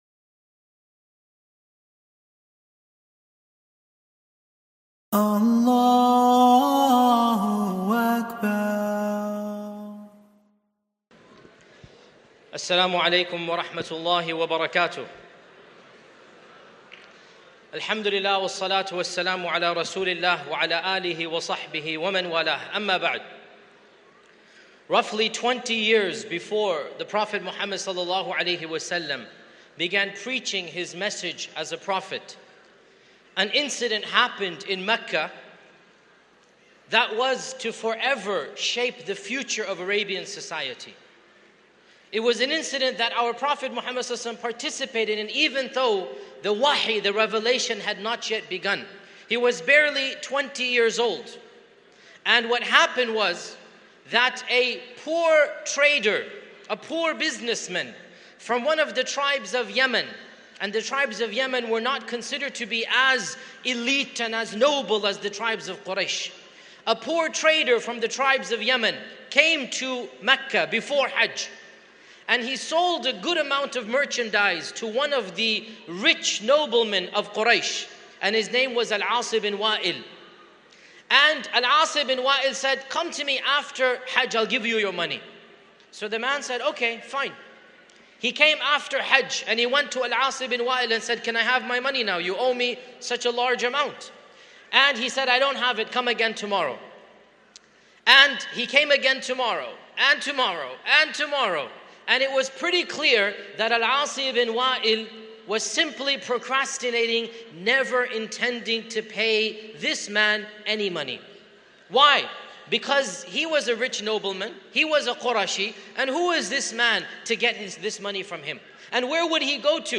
In the final session for Sunday night Shaykh Yasir Qadhi in a truly motivational lecture which brought the entire audience to their feet at the end.